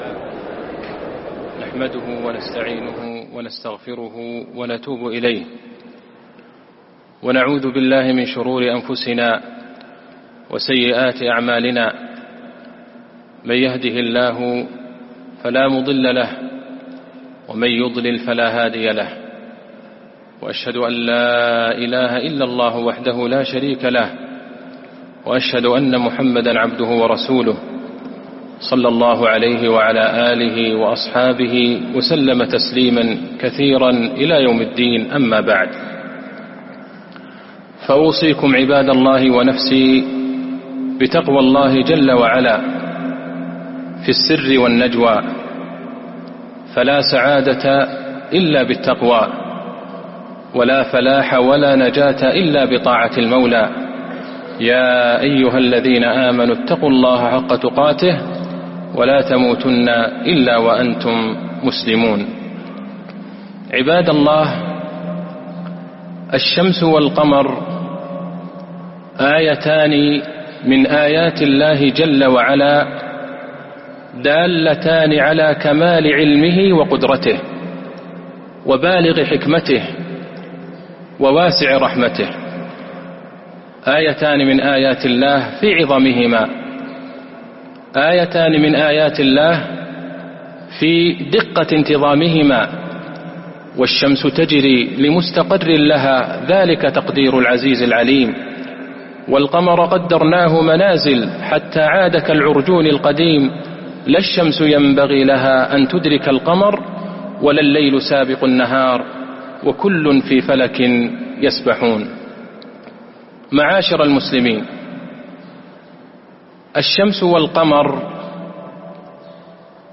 خطبة الكسوف المدينة - الشيخ عبدالله البعيجان
المكان: المسجد النبوي